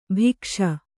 ♪ bhikṣa